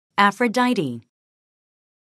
[ӕfrədáiti]